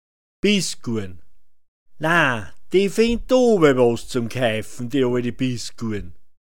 Bißgurrn [‚bizguan] f